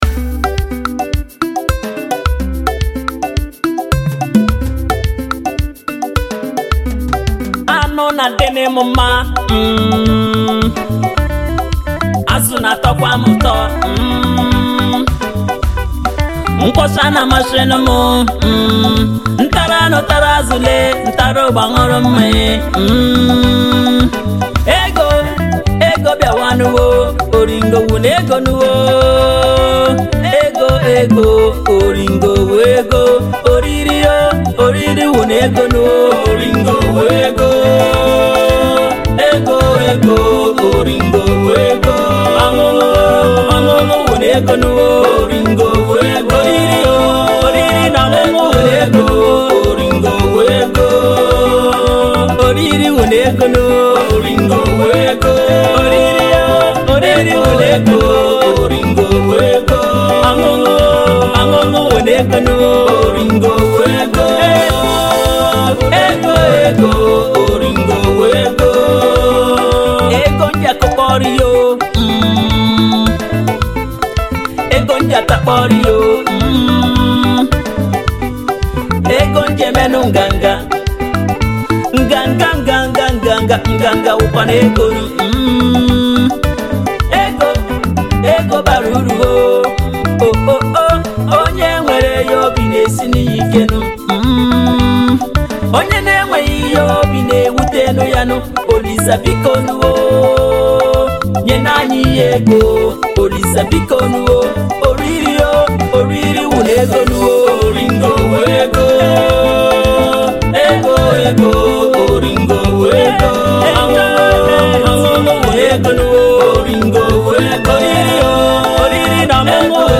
His melody and harmony is straight to the soul.